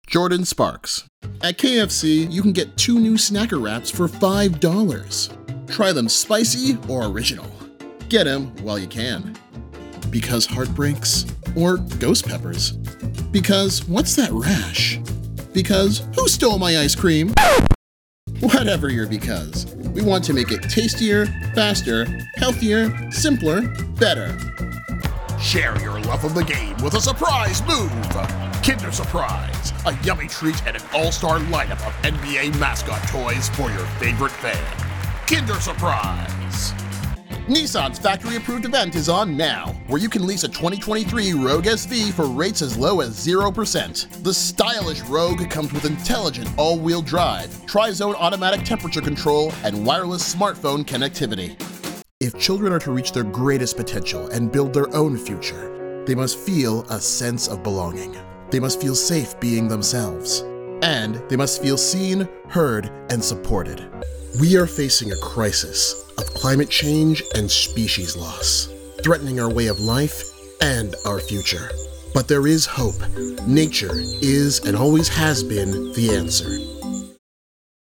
Among my skills, I have strong experience in audio production and doing voice-over performances from my home studio.
Commercial Reel
CommercialReel2024.mp3